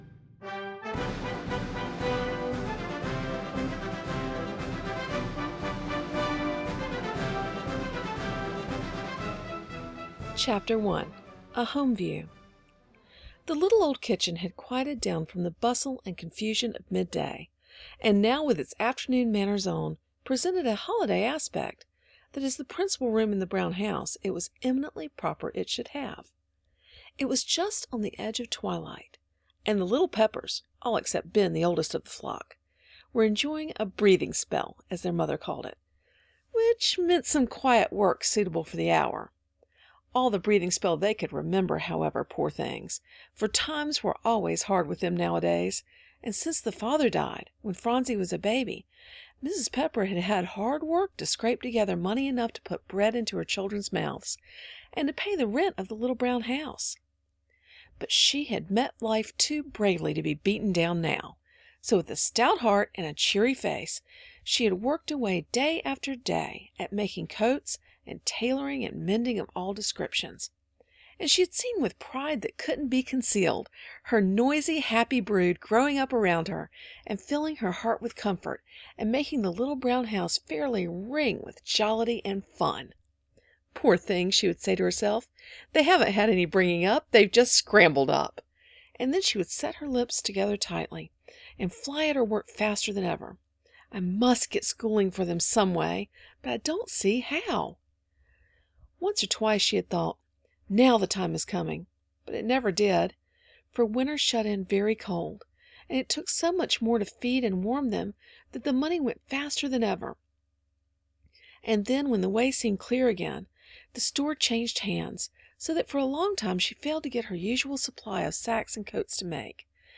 Two sweet and funny audiobooks that will help your family learn to be content and to love people more than things!